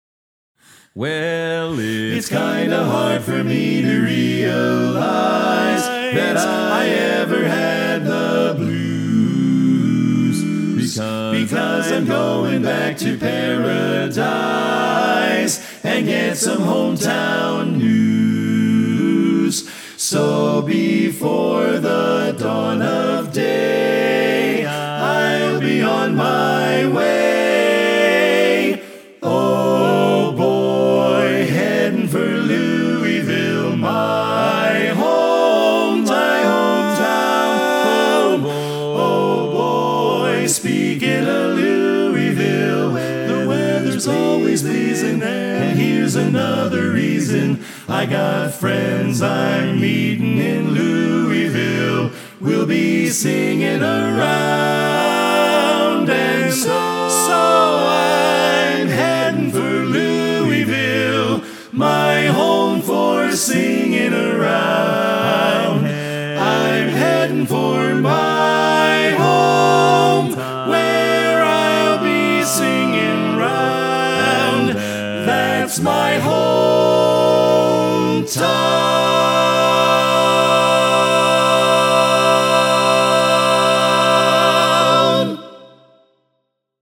Kanawha Kordsmen (chorus)
Barbershop
Full Mix